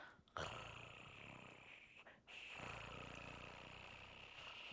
学习滚动R和扑通声 " 扑通声旅程4
整个舌头都在拍打着。听起来不像是R，再加上需要太多空气......
标签： 扑动 无障碍 R 呼噜声
声道立体声